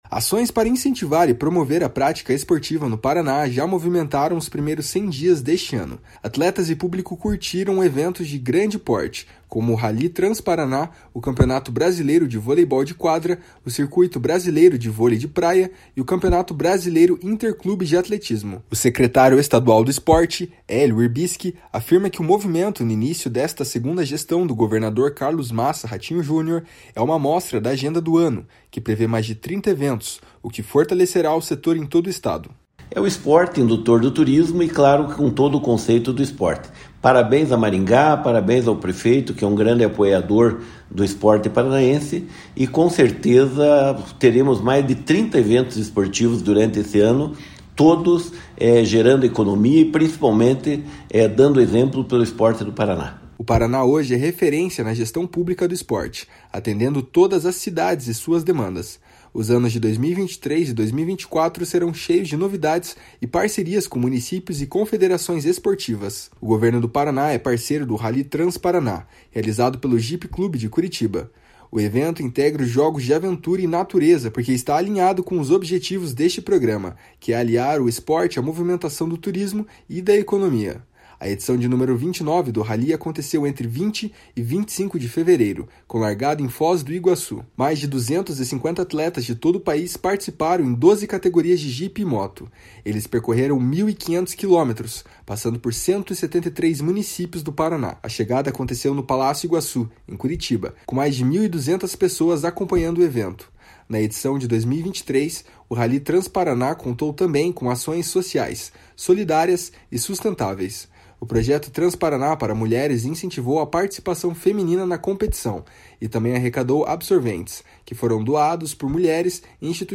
// SONORA HÉLIO WIRBISKI //